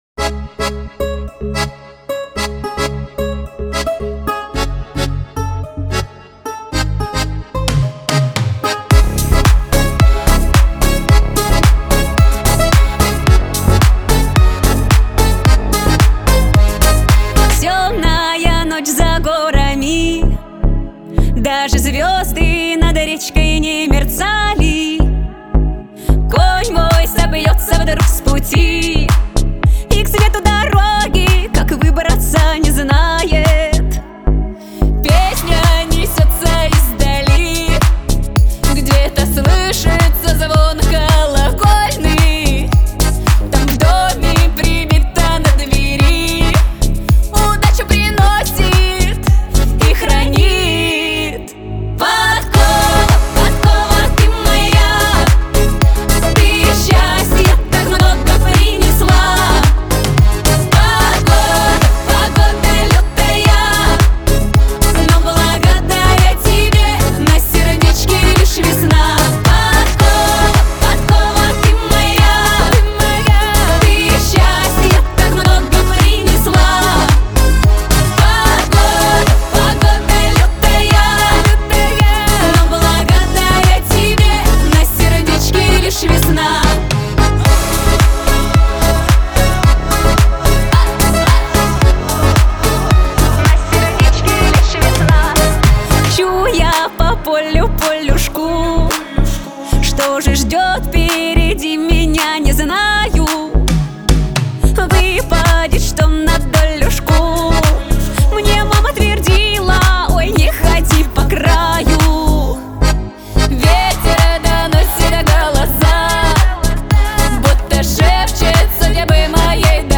Лирика , ансамбль